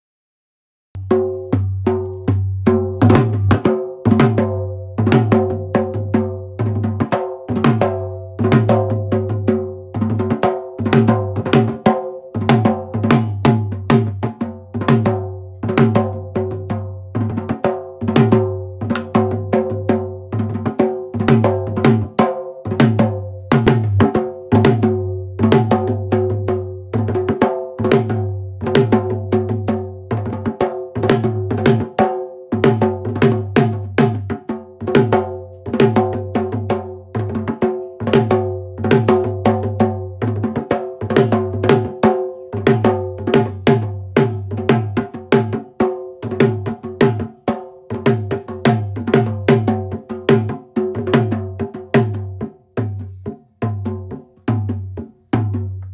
The drum is called Dhul by the Morans because it plays loudly.
Instrument with two men